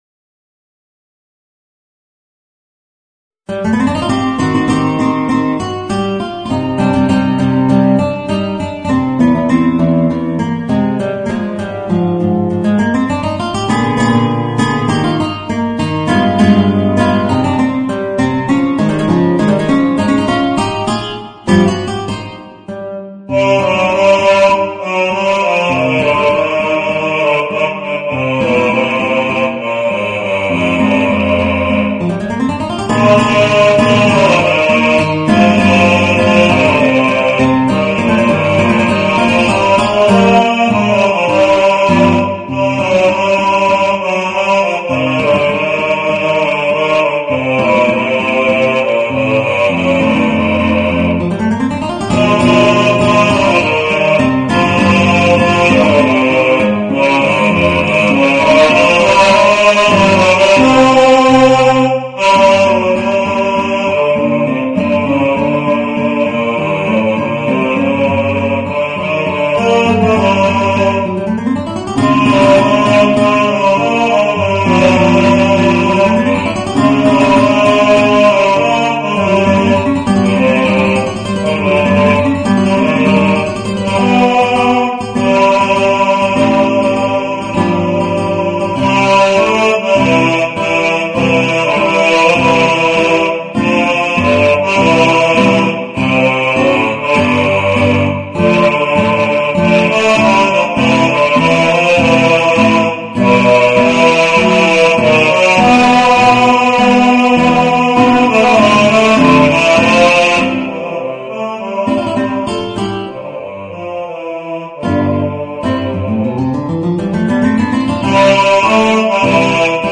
Voicing: Guitar and Bass